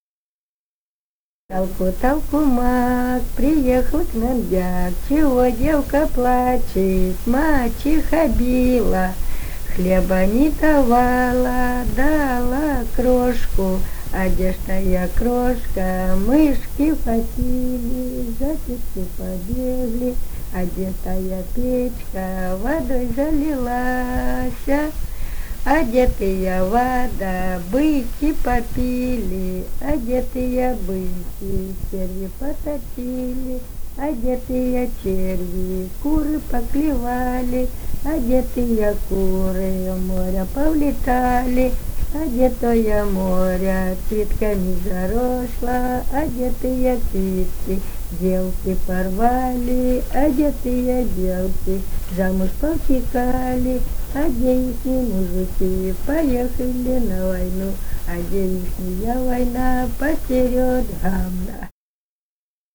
полевые материалы
«Толку, толку мак» (потешка).
Румыния, с. Переправа, 1967 г. И0973-15